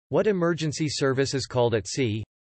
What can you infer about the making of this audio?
Sample: You will hear a question.